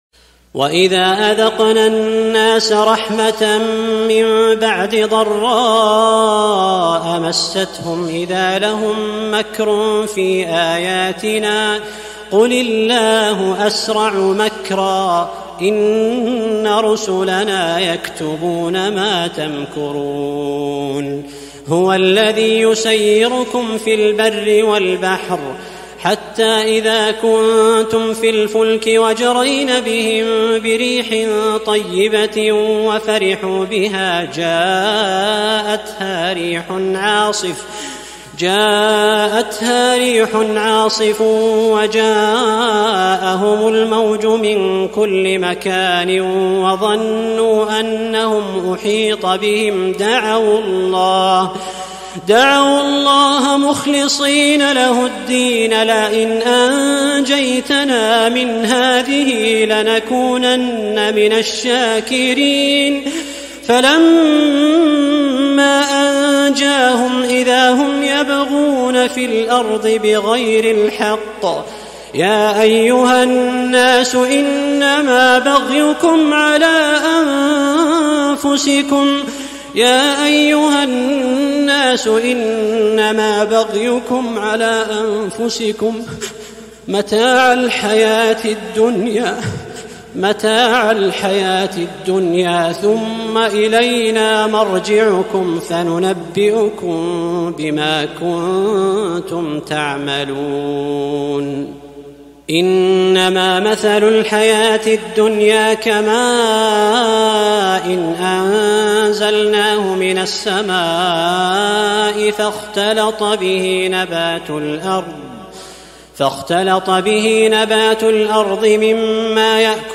تلاوة خاشعة من سورة يونس للقارئ